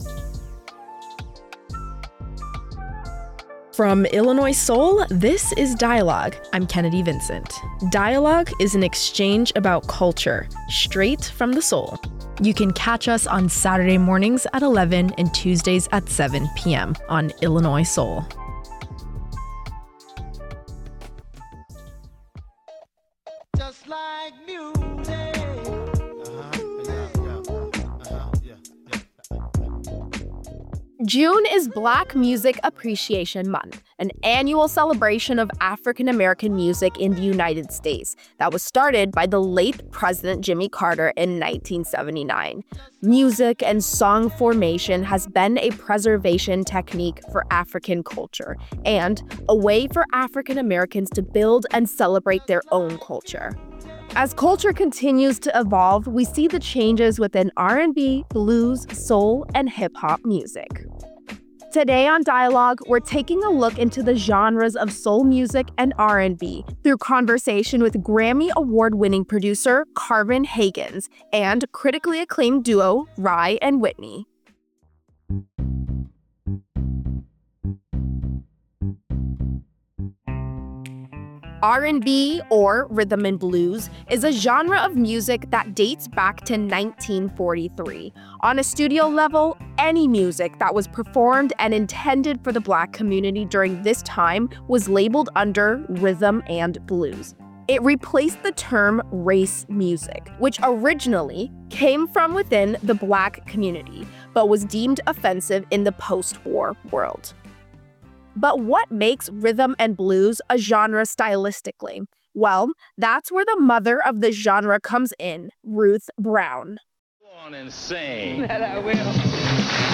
You'll hear from local and national artists who have helped pave the way for future young Black musicians.